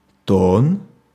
Ääntäminen
IPA: [tɔ̃]